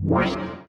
snd_mage_summon_shield.ogg